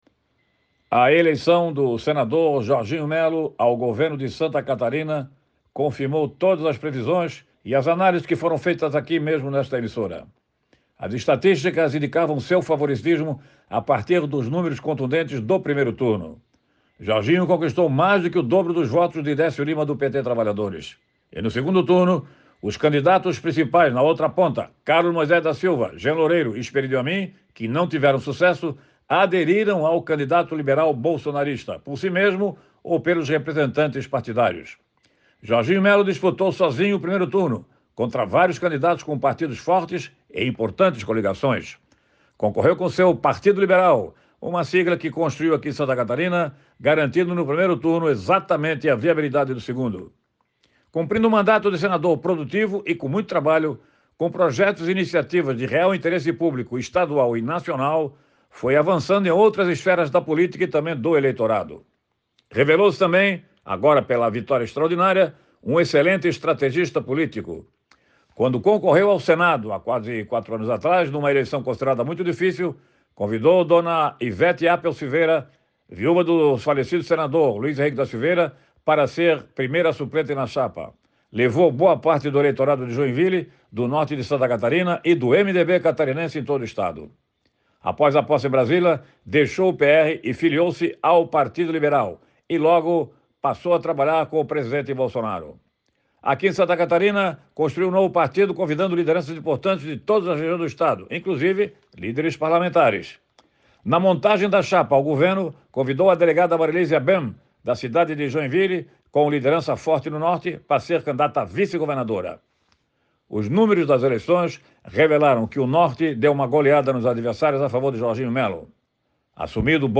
Jornalista destacou os números contundentes de Jorginho Mello (PL) para chegar ao governo do Estado